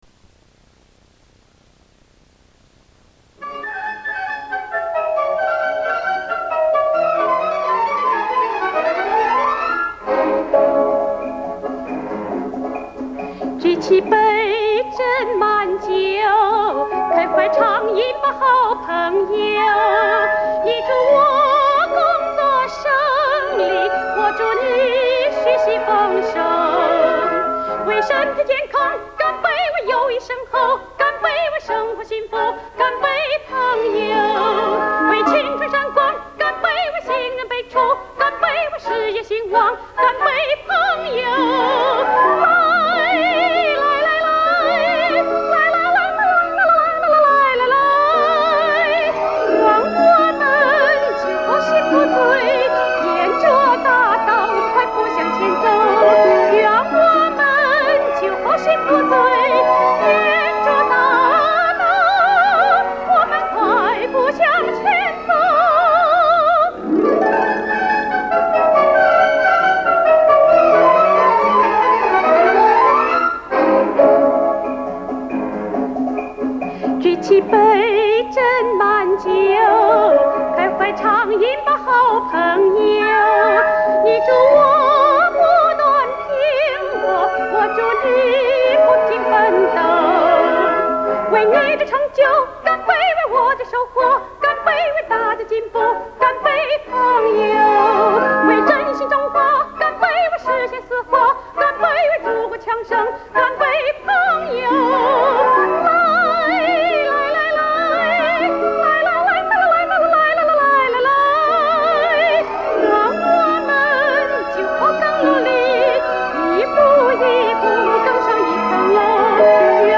女高音